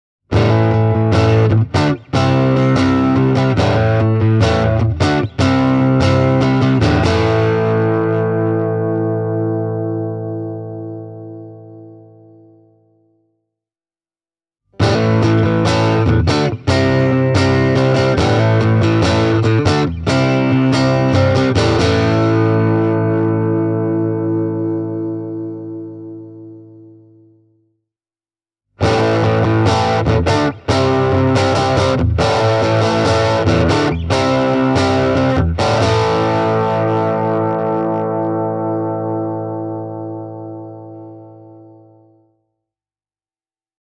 Tweed-tyylinen kombo, särö:
fender-am-pro-telecaster-e28093-tweed-grit.mp3